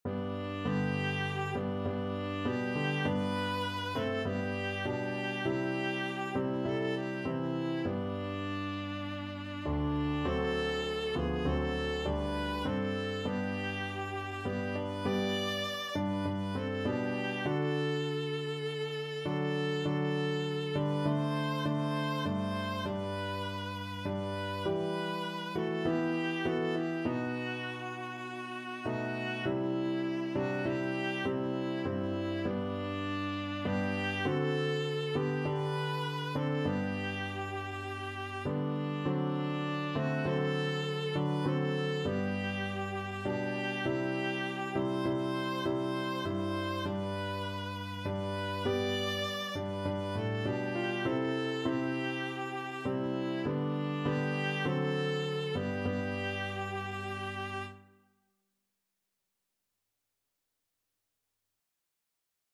Viola version
Christian
4/4 (View more 4/4 Music)
Viola  (View more Easy Viola Music)
Classical (View more Classical Viola Music)